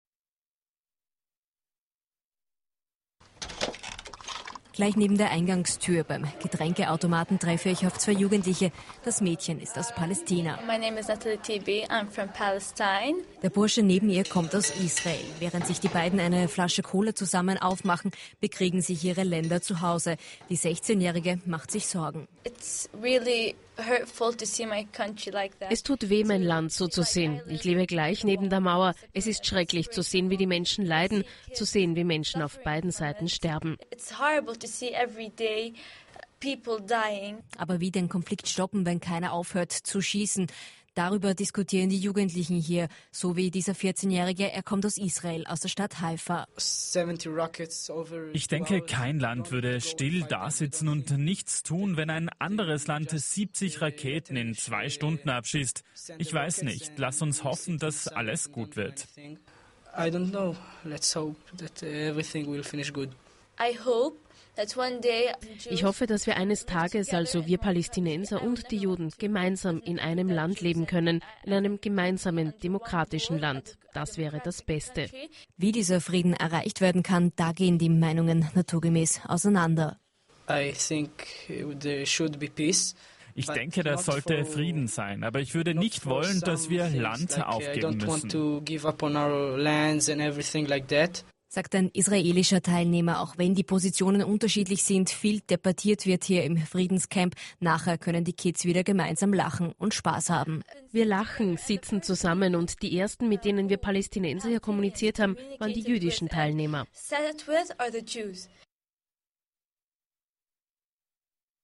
Report in main news broadcast "Mittagsjournal" (1:51): please click on OE1_Mittagsjournal120714 (audio/mp3, 4.362 KB)